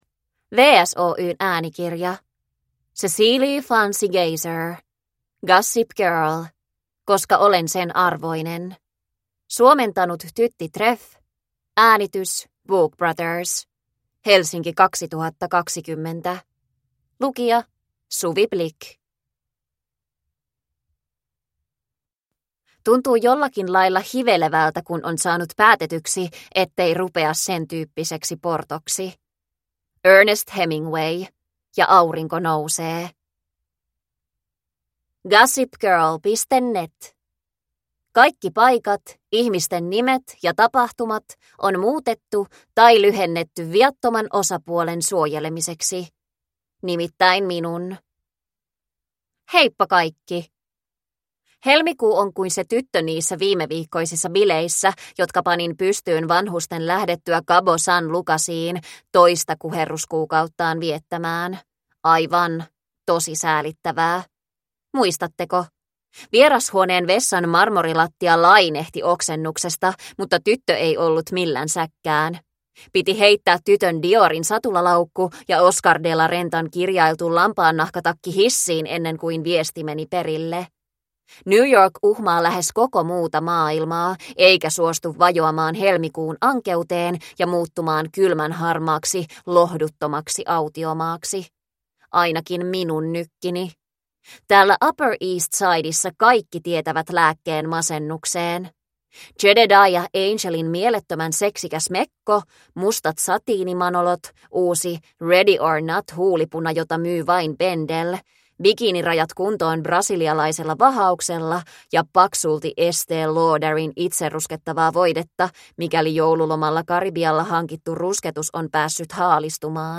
Koska olen sen arvoinen – Ljudbok